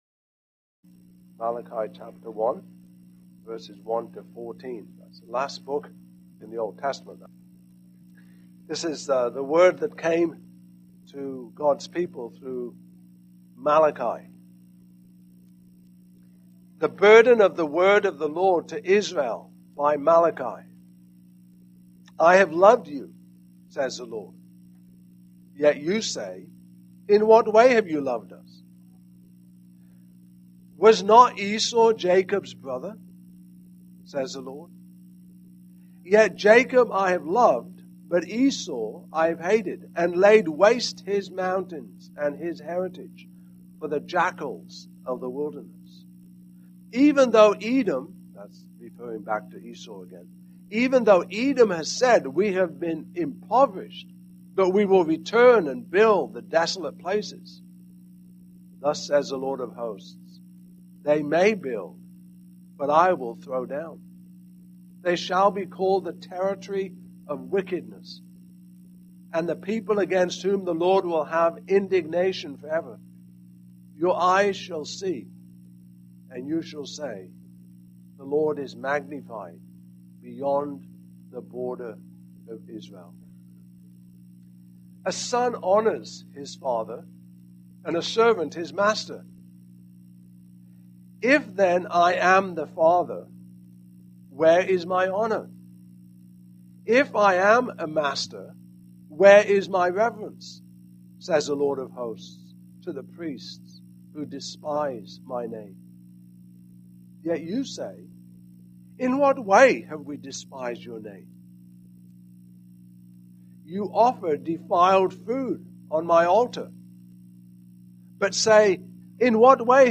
Series: New Year’s Eve Sermon
Service Type: Morning Service